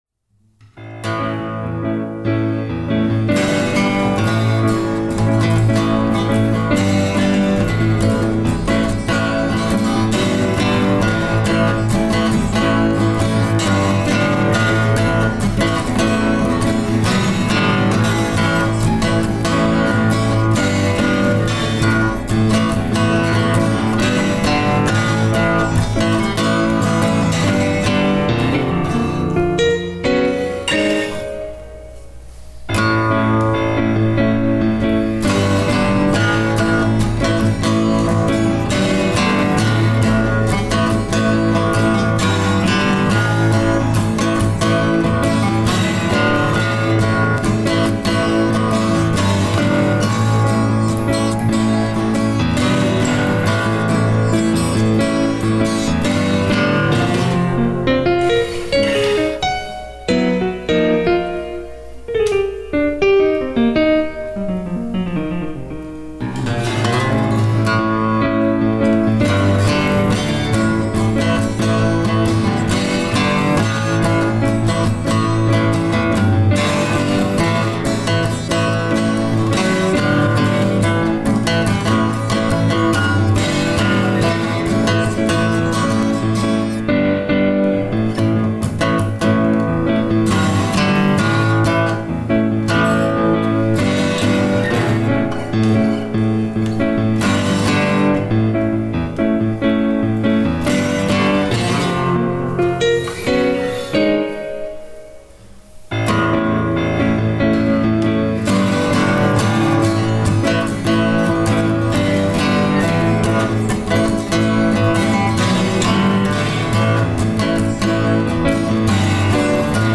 a rock’n roll piece entitled Phonetic.
piano
acoustic guitars and bass